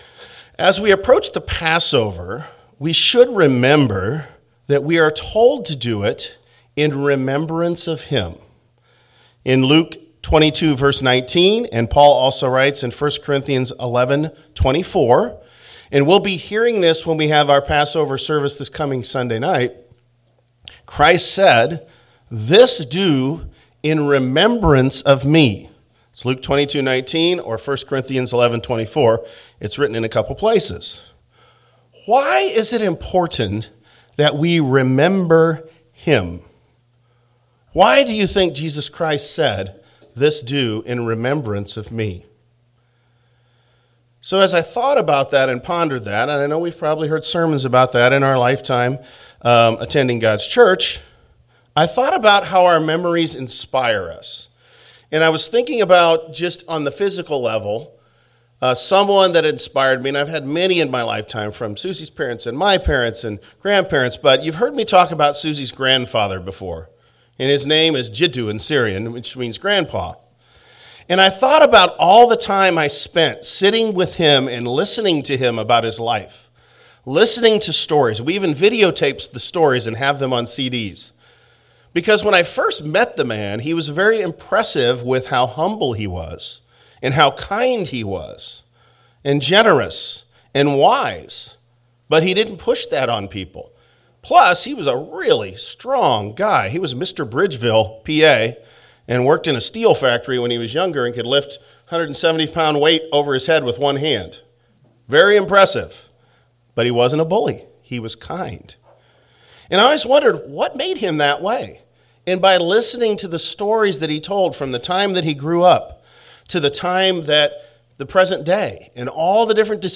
Given in Omaha, NE